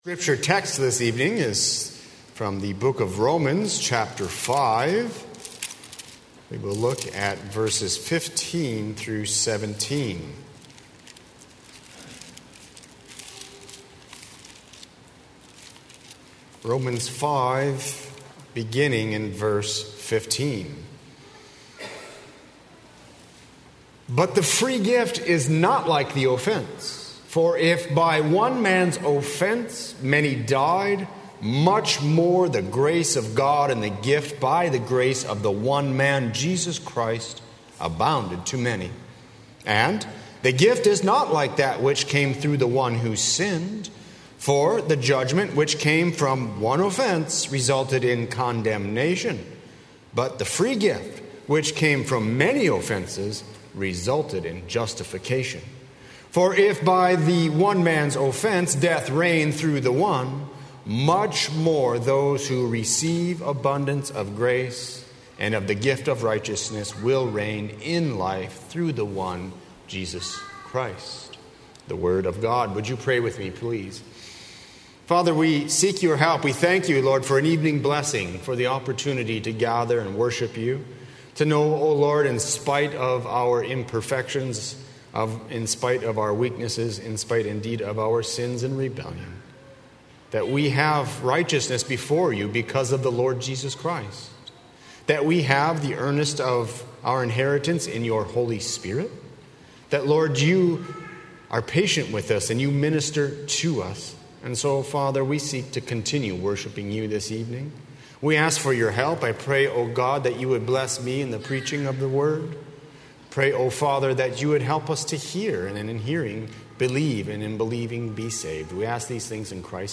00:00 Download Copy link Sermon Text Romans 5:15–17